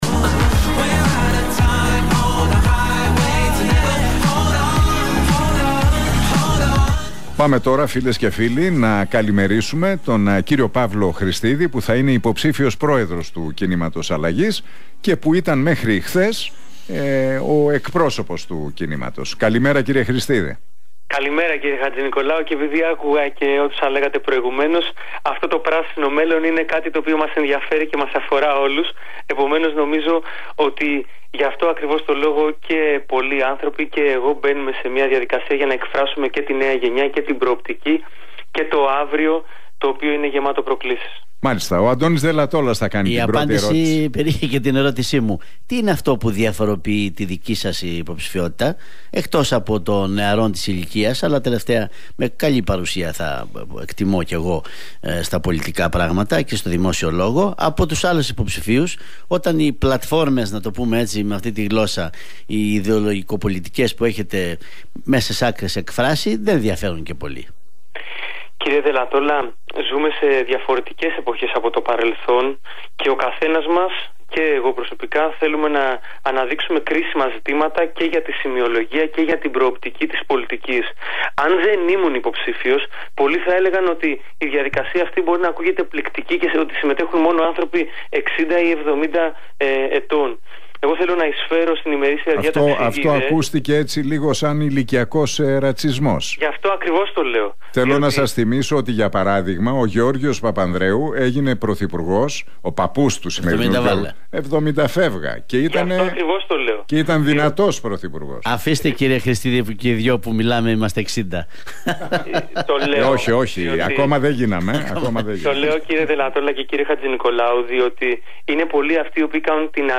Χρηστίδης στον Realfm 97,8: To πράσινο μέλλον είναι αυτό που μας ενδιαφέρει και μας αφορά όλους